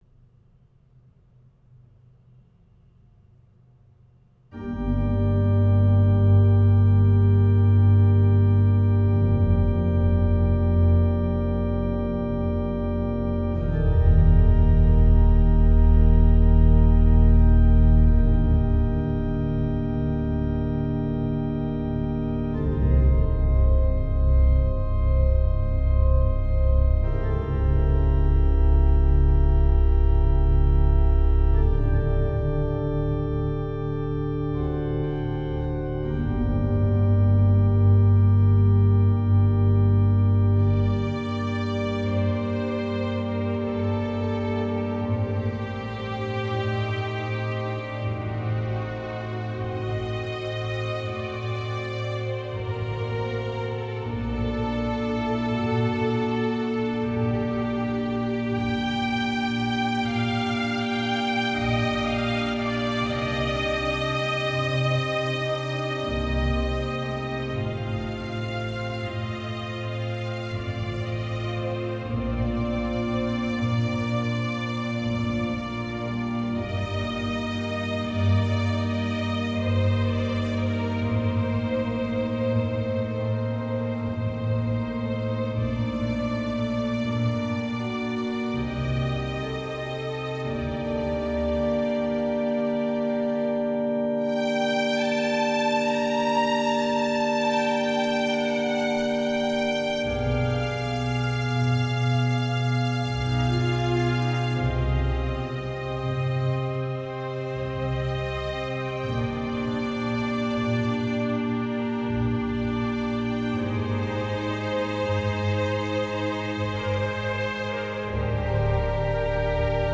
Albinoni Adagio for Strings and Organ